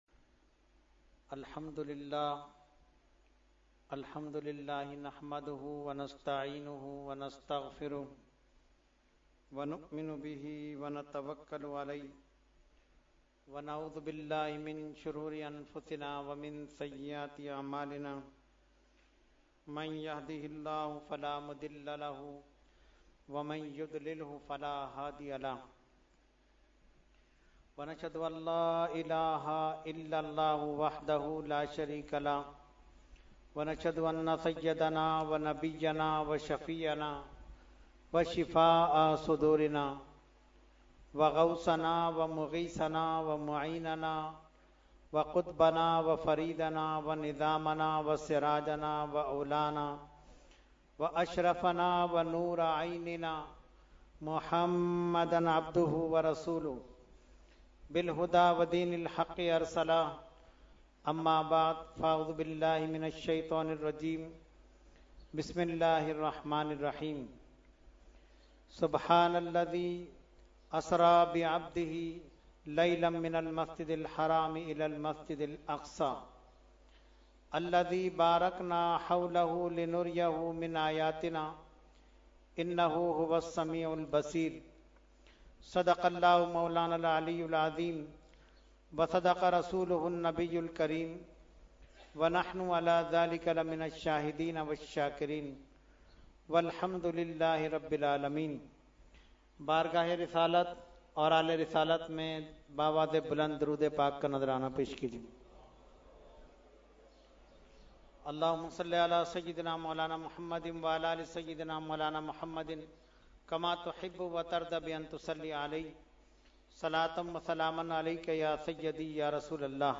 Category : Speech | Language : UrduEvent : Shab e Meraj 2018